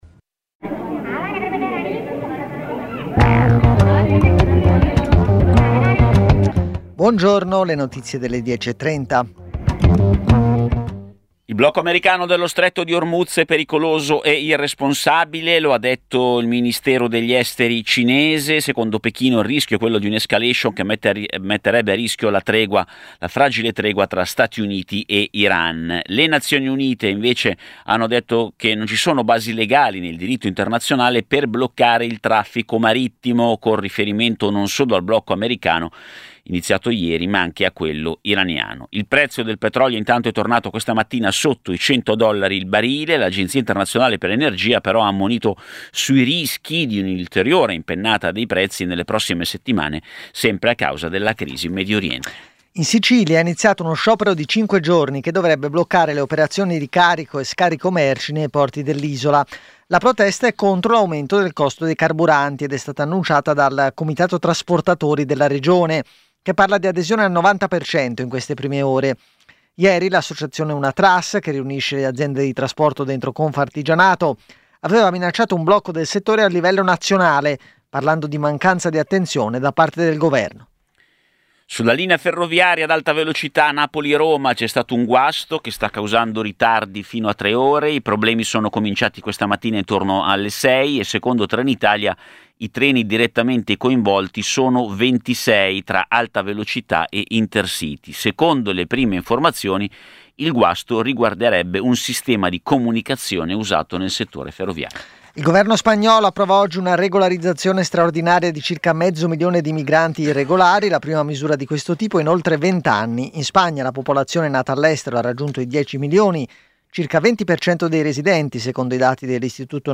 Edizione breve del notiziario di Radio Popolare. Le notizie. I protagonisti. Le opinioni. Le analisi.